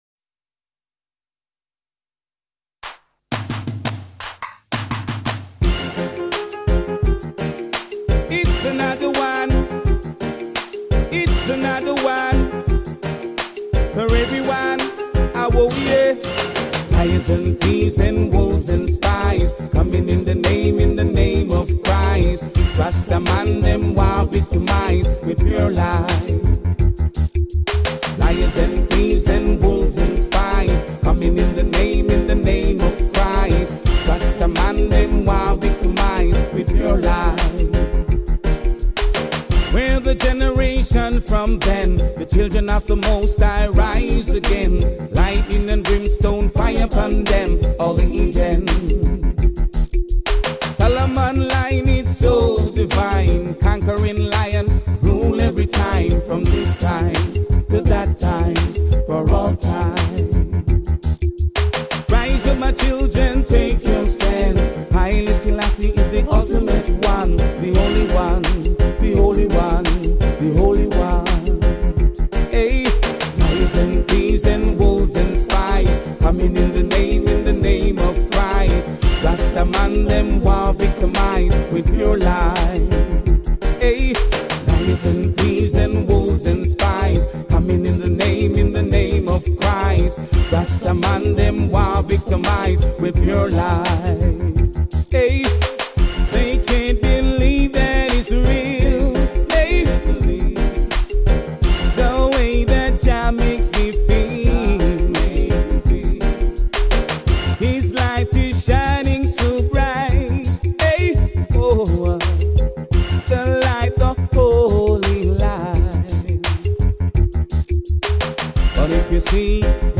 Distinctive voice